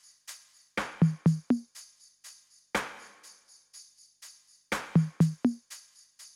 Подскажите, как получить такую перкуссию
Интересует булькающий звук.